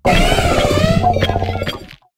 irontreads_ambient.ogg